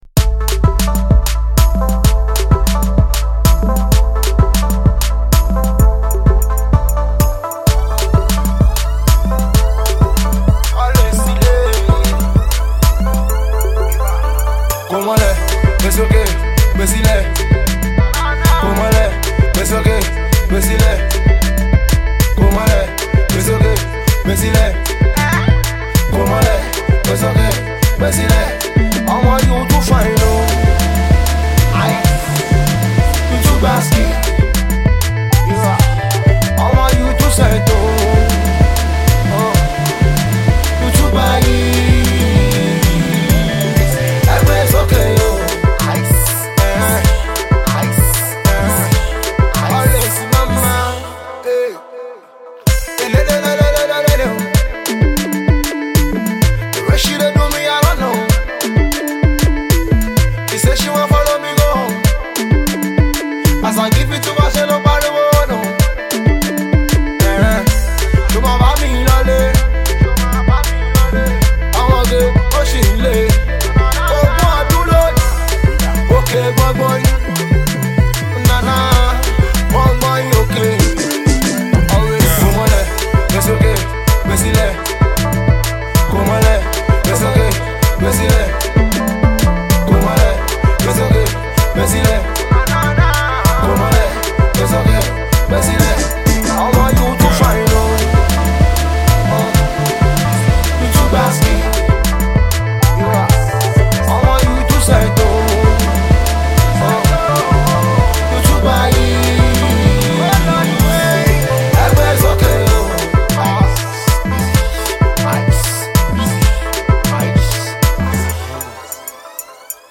a certified club jam and dance floor filler